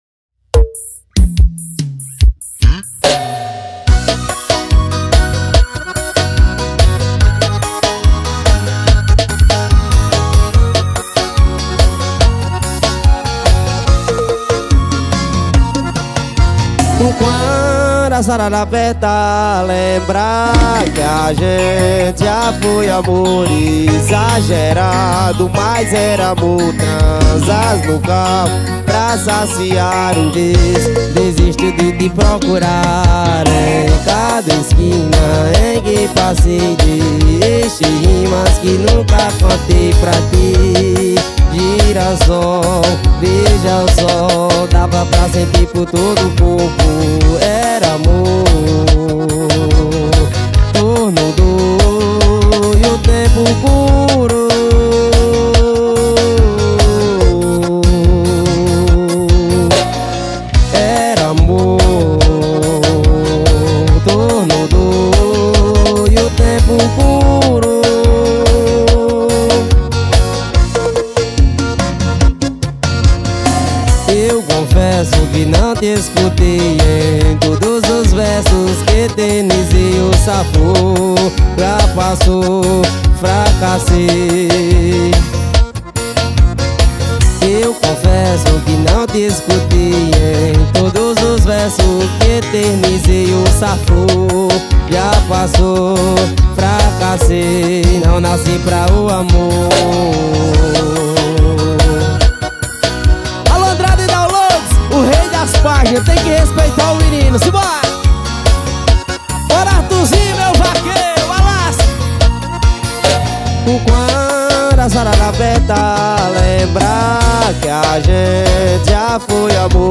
2025-01-23 11:30:20 Gênero: Forró Views